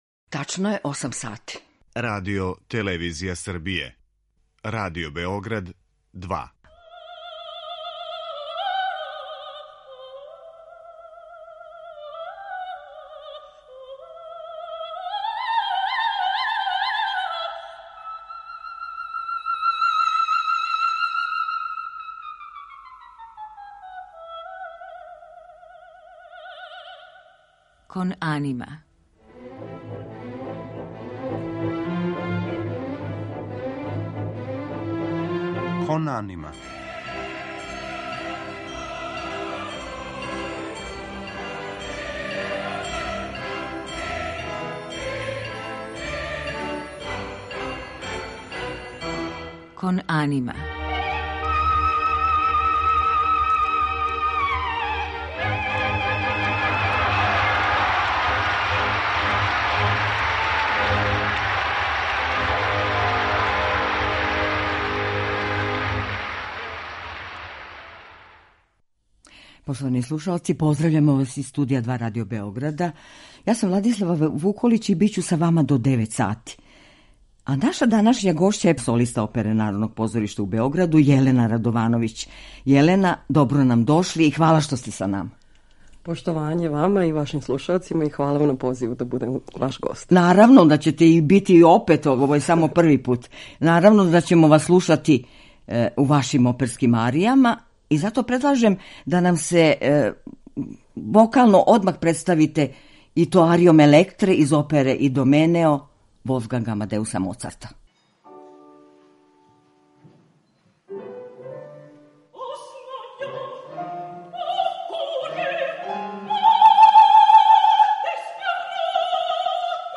како пева арије из Вердијевих, Пучинијевих, Моцартових и Вагнерових опера